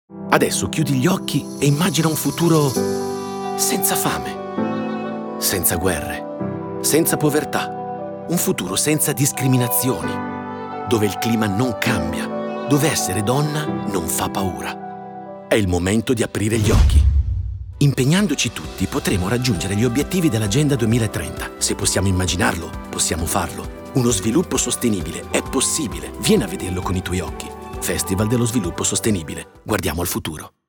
Lo spot radio
Lo spot, il cui voice over per il secondo anno consecutivo è stato realizzato da Fabio Volo, invita prima a chiudere gli occhi e a immaginare un mondo senza fame, senza guerre, senza povertà.